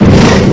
Touch-Nose.wav